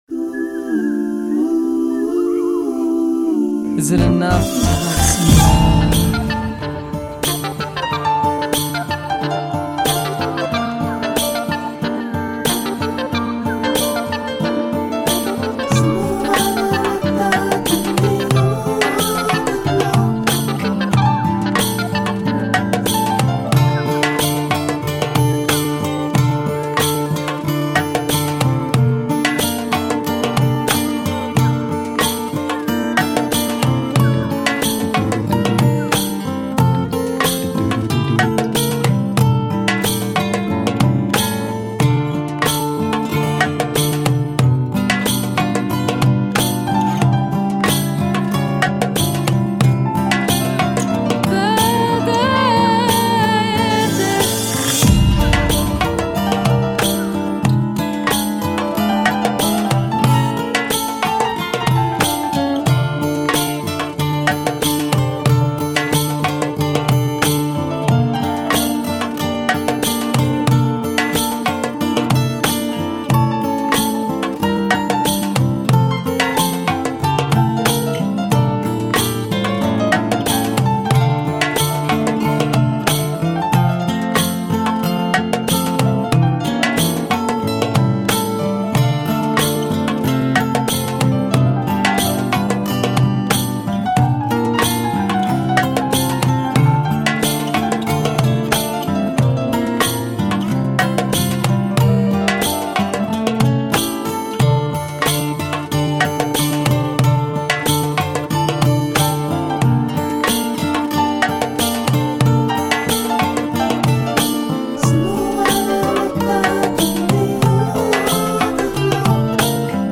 Chosen for the original, slower tempo used with the guitar sample and his nice piano work over guitar theme.